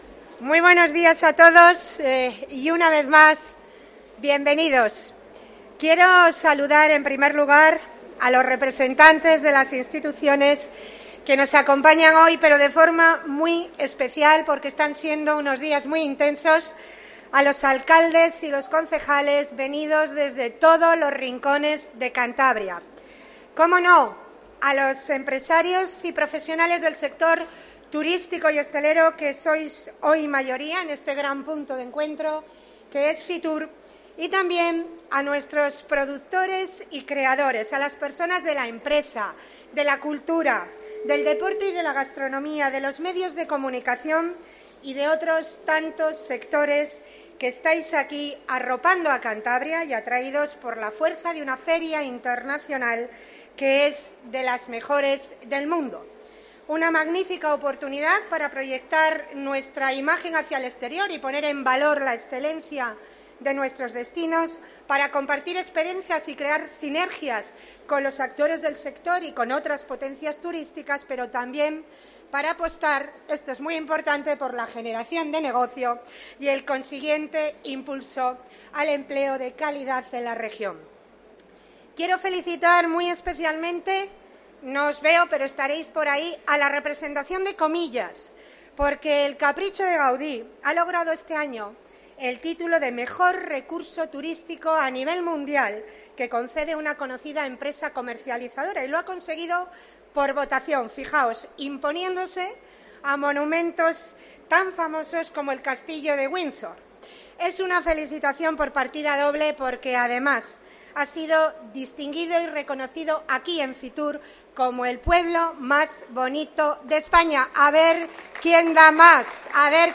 Puedes escuchar el discurso completo de María José Sáenz de Buruaga pinchando debajo de la fotografía .